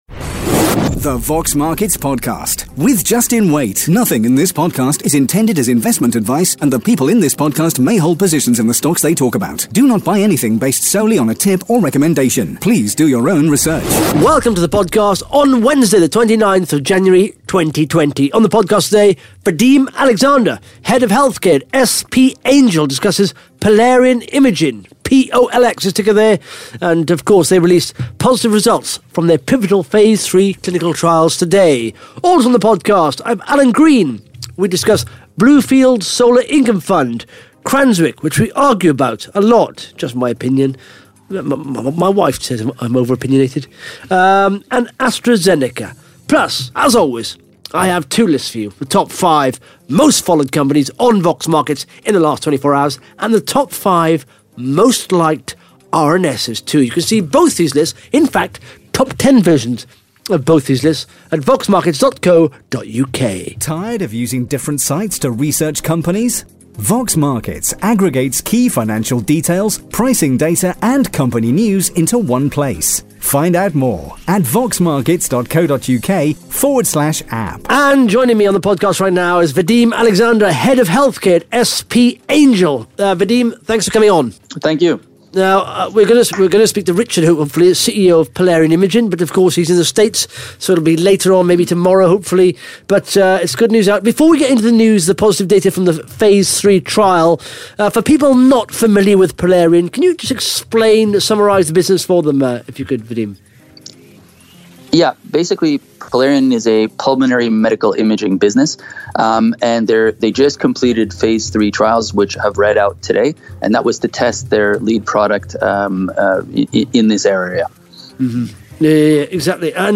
(Interview starts at 14 minutes 54 seconds)